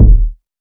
KICK.66.NEPT.wav